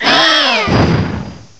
cry_not_torracat.aif